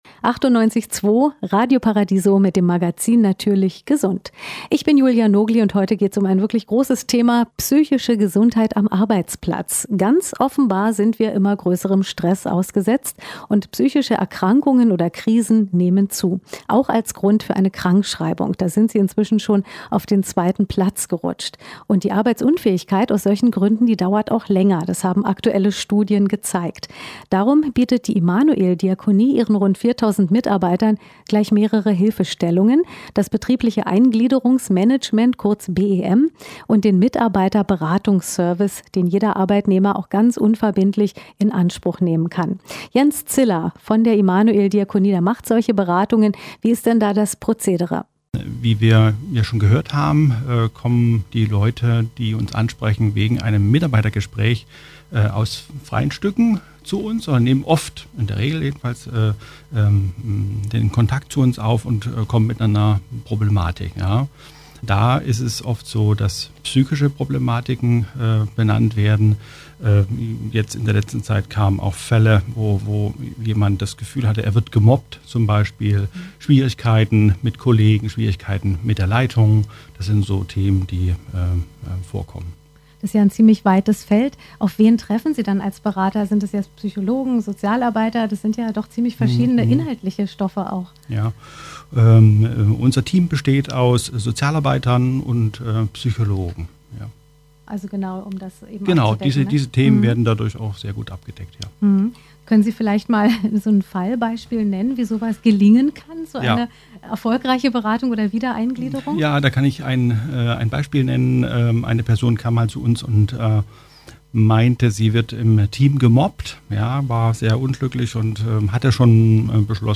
Claudia Roth, ehmalige Bundesvorsitzendene der Partei Bündnis 90/Die Grünen, im Gespräch mit Mitarbeitern der Familienberatung Marzahn von Beratung + Leben.